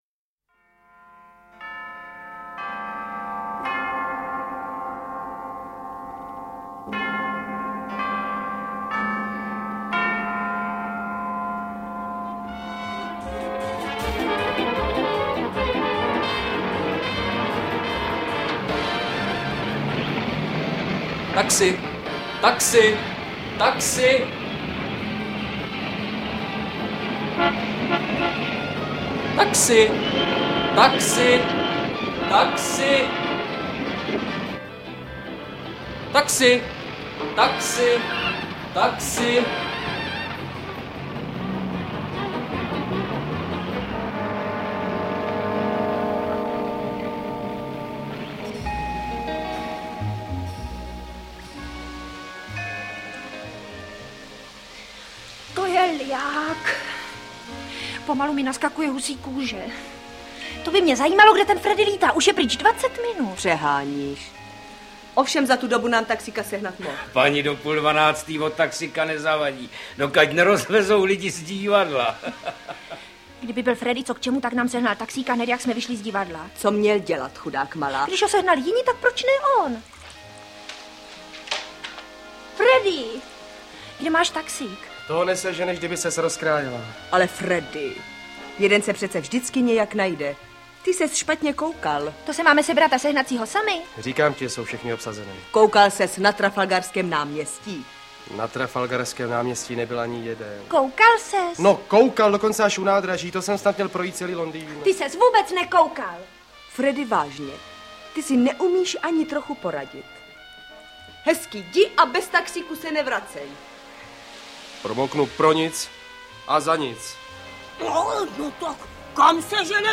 Interpreti:  Jiřina Bohdalová, Miloš Kopecký
Rozhlasová nahrávka nejslavnější hry anglického dramatika G. B. Shawa verze vychází z inscenace, která měla premiéru 2. února 1968 v Divadle na Vinohradech a stala se trhákem sezóny.